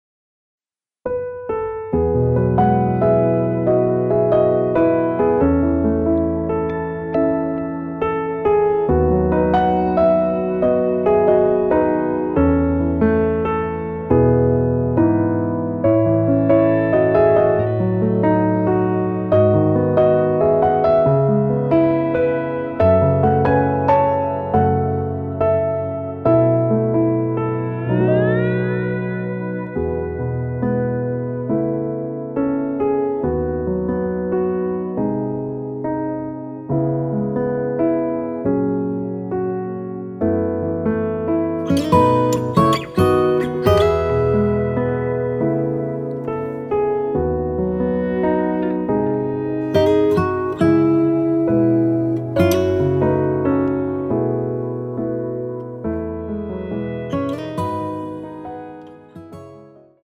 [공식 음원 MR]
앞부분30초, 뒷부분30초씩 편집해서 올려 드리고 있습니다.
중간에 음이 끈어지고 다시 나오는 이유는